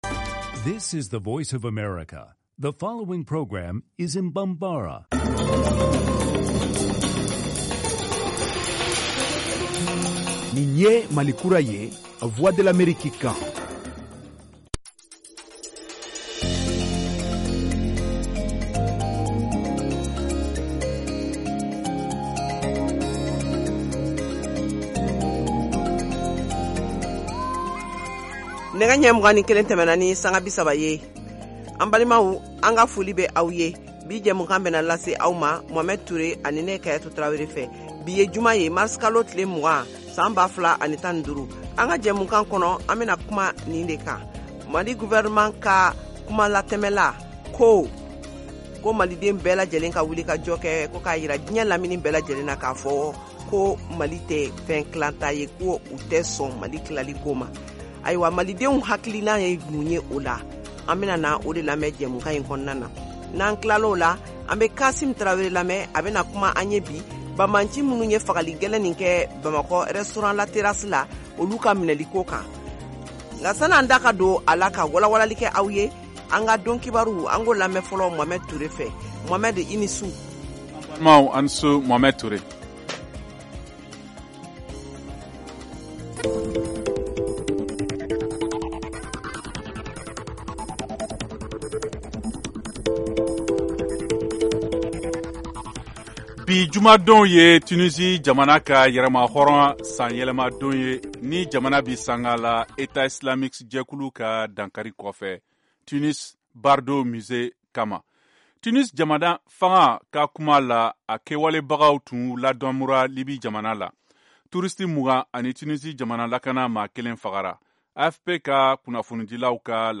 Emission quotidienne
en direct de Washington, DC, aux USA